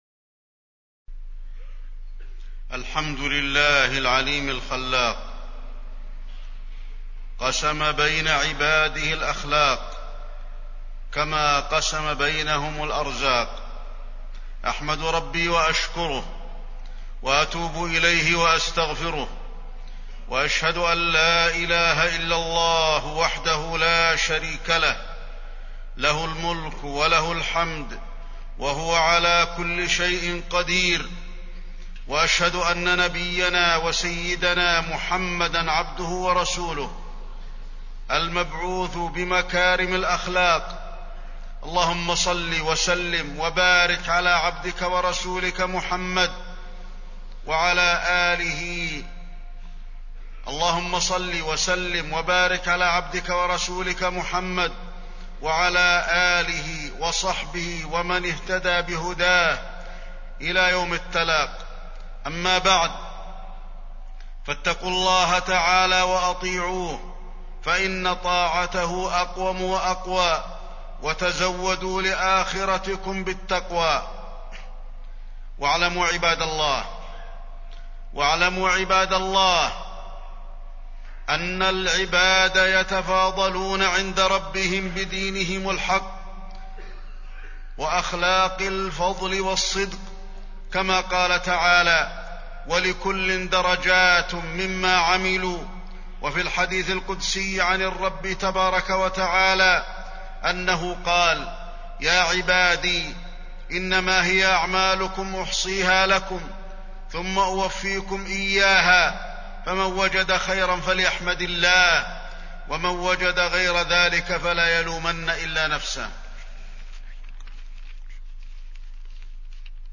تاريخ النشر ٢٧ جمادى الأولى ١٤٢٧ هـ المكان: المسجد النبوي الشيخ: فضيلة الشيخ د. علي بن عبدالرحمن الحذيفي فضيلة الشيخ د. علي بن عبدالرحمن الحذيفي الصبر